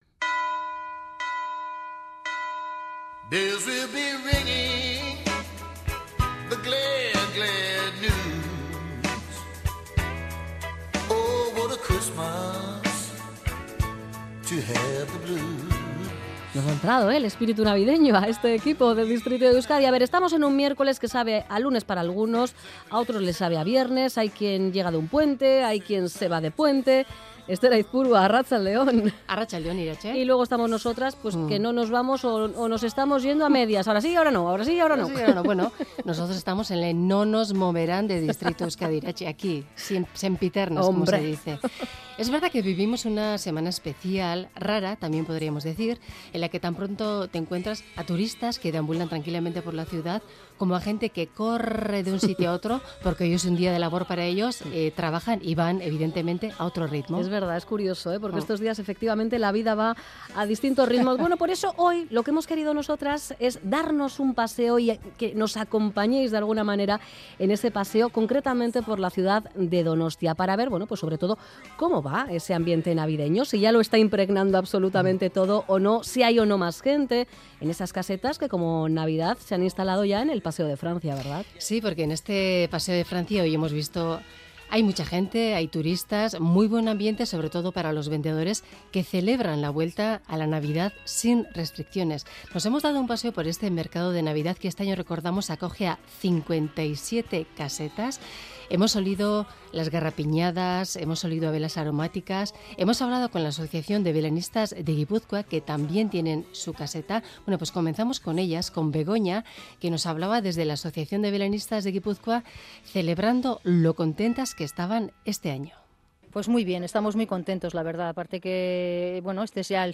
Nos acercamos a las casetas de Navidad de Donostia, y charlamos con quienes están en la caseta dedicada a Ucrania.